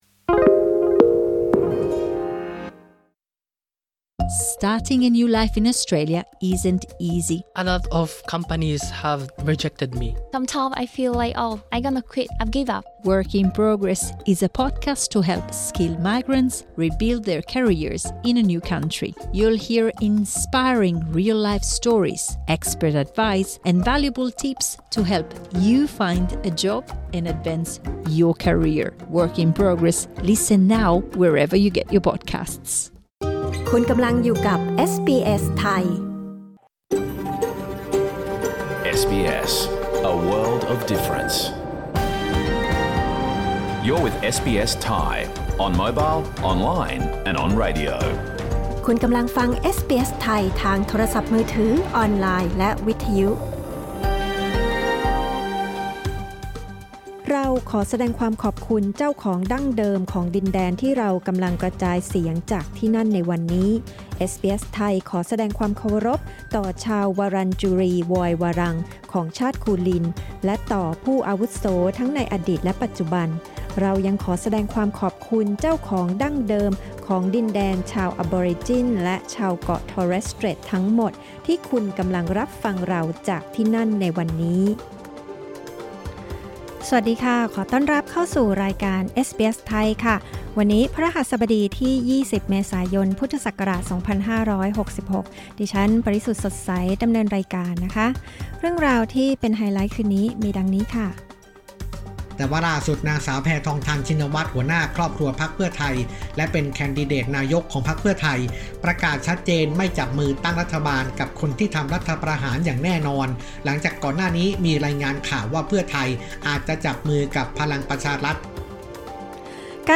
รายการสด 20 เมษายน 2023
ชวนคุยกับ ภูมิ วิภูริศ นักร้องอินดี้คนไทยที่ดังไปทั่วโลก กำลังจะมาแสดงคอนเสิร์ตในออสเตรเลียสิ้นเดือนนี้ พร้อมเรื่องเงินสวัสดิการผู้หางาน Jobseeker และรายงานสายตรงจากเมืองไทยติดตามกระแสข่าวที่บางพรรคเล็งจับขั้วตั้งรัฐบาลกันแล้ว